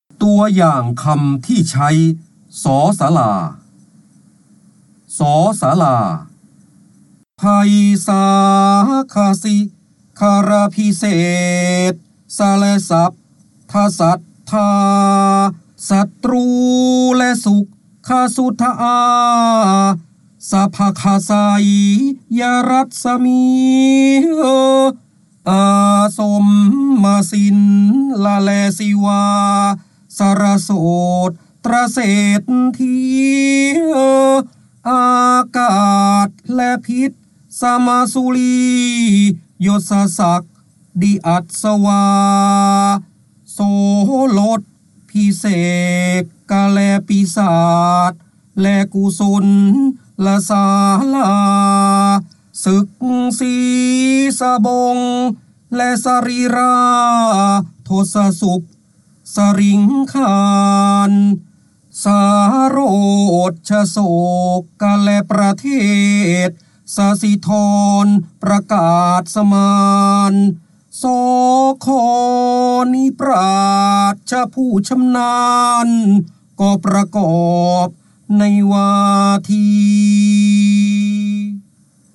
เสียงบรรยายจากหนังสือ จินดามณี (พระโหราธิบดี) ตัวอย่างคำที่ใช้ ศ
ลักษณะของสื่อ :   คลิปการเรียนรู้, คลิปเสียง